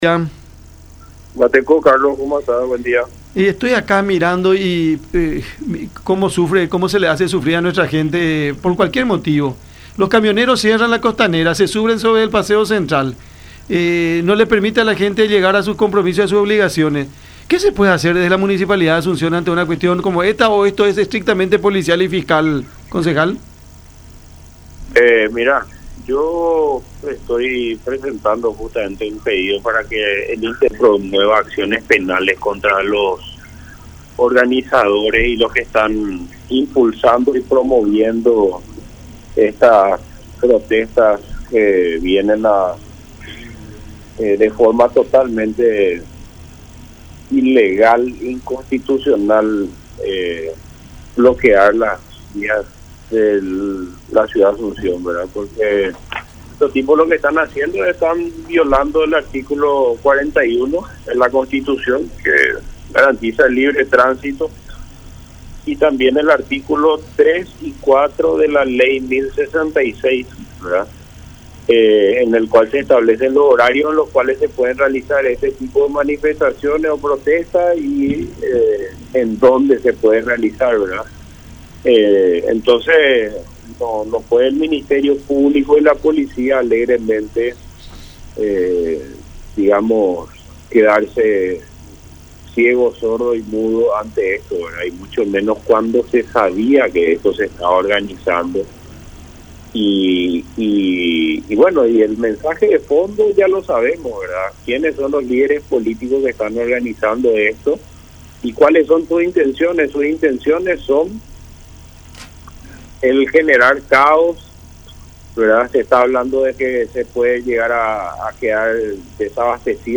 “Estoy presentando un pedido para que el intendente accione penalmente contra los camioneros que cierran el acceso a la ciudad de Asunción. Se está violando el derecho de libre circulación de las personas”, dijo Grau en conversación con Cada Mañana a través de La Unión.